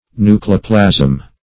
Nucleoplasm \Nu"cle*o*plasm\, n. [Nucleus + -plasm.] (Biol.)